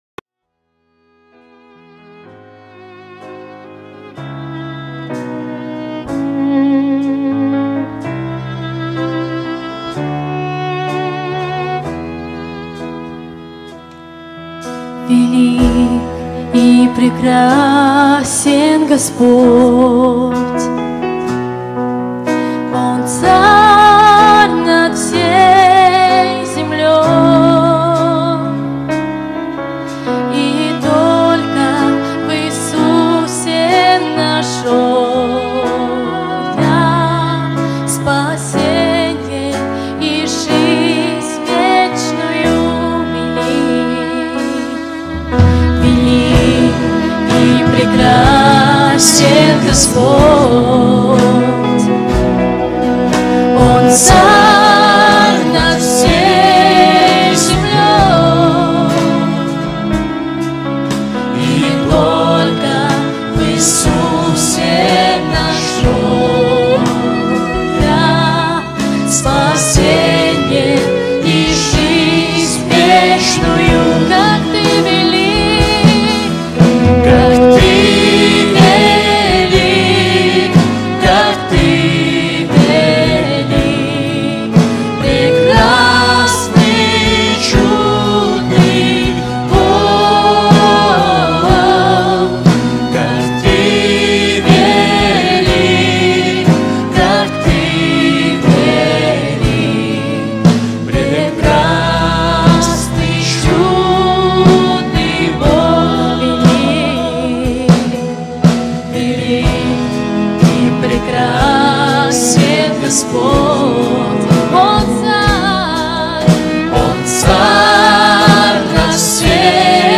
117 просмотров 162 прослушивания 6 скачиваний BPM: 68